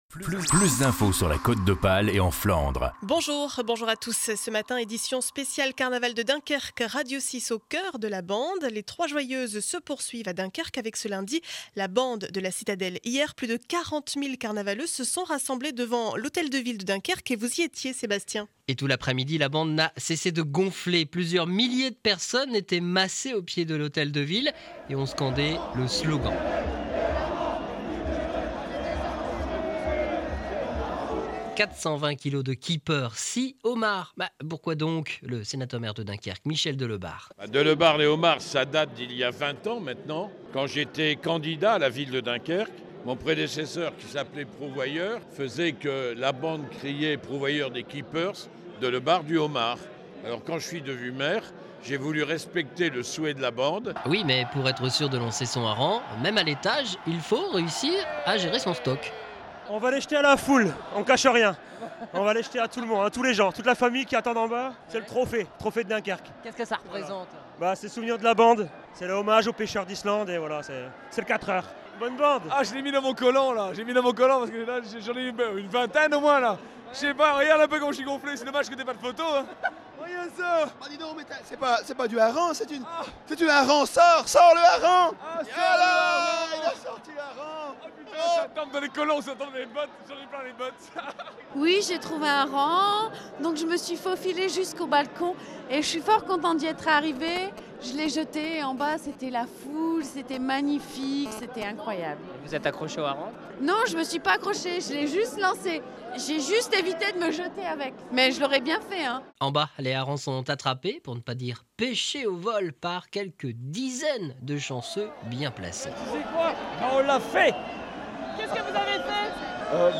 Journal du lundi 20 février 2012 8 heures 30 édition du Dunkerquois.